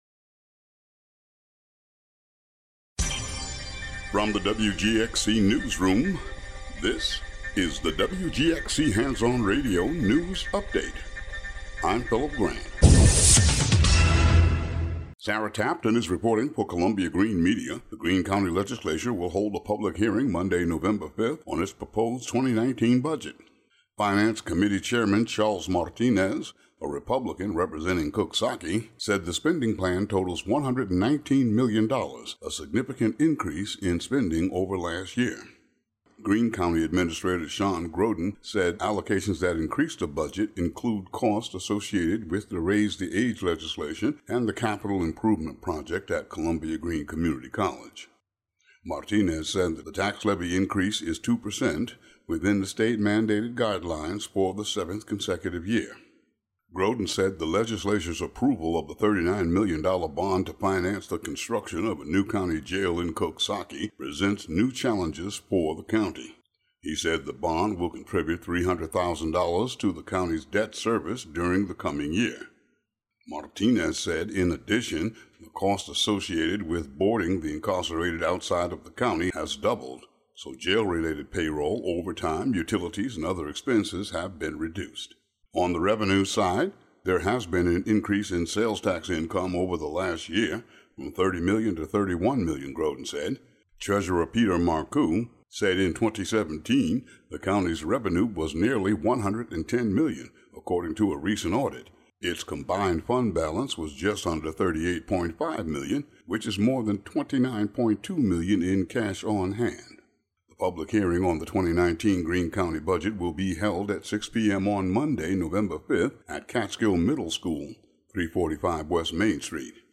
News update for the area.